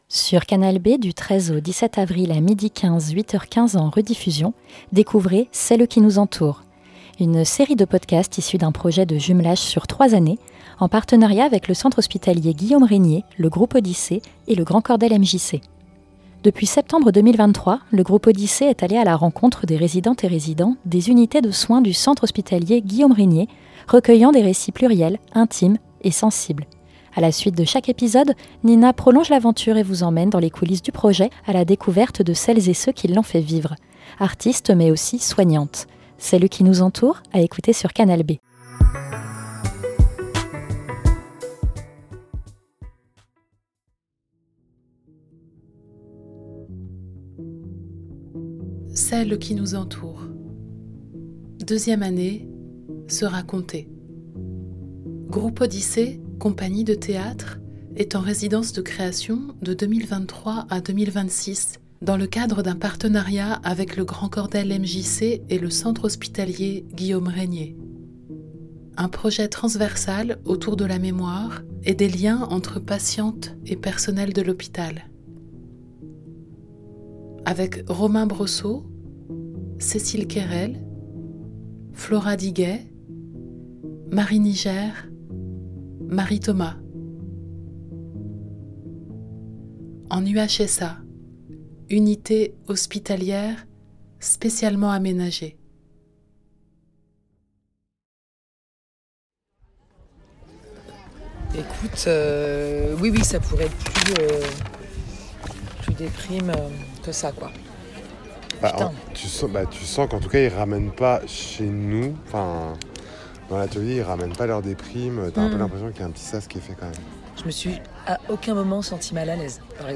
Restitution sonore
C'est à l'unité hospitalière spécialement aménagée de Rennes que ce projet pose ses valises pour le dernier épisode d'une série de cinq restitutions sonores. Interview A la suite de chaque épisode